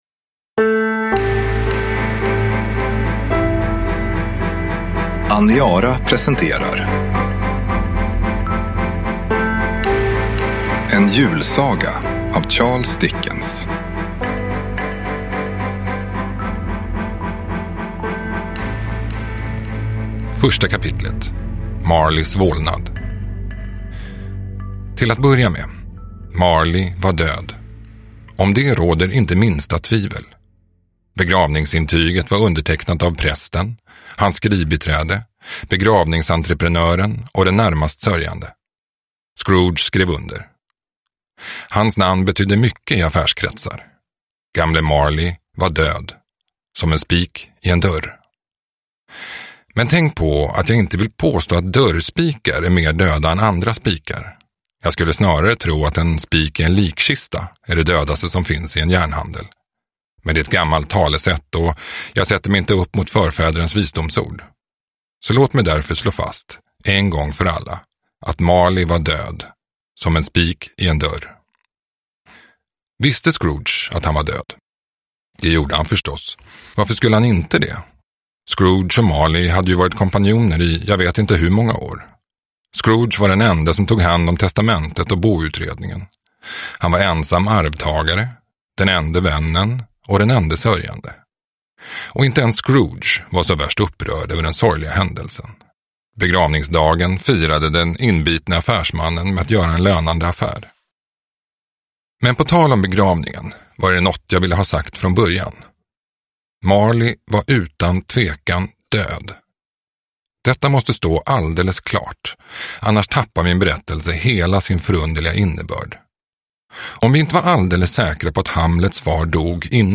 En julsaga – Ljudbok